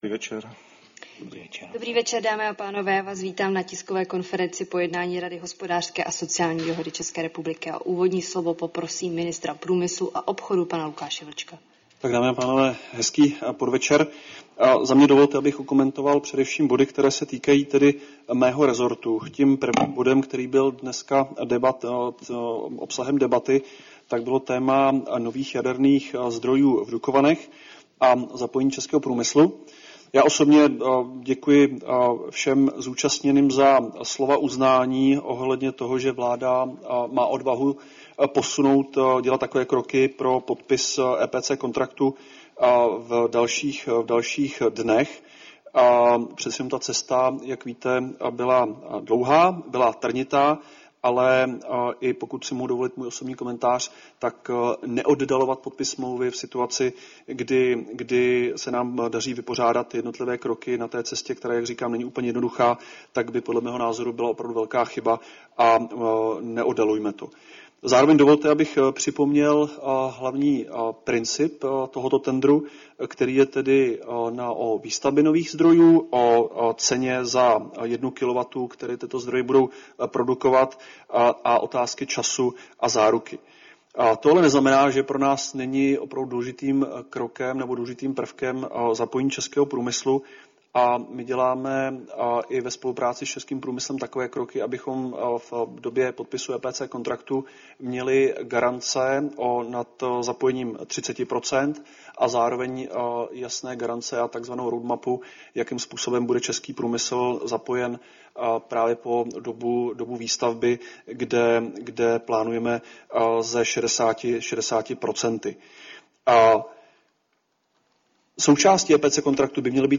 Tisková konference po 179. plenární schůzi Rady hospodářské a sociální dohody ČR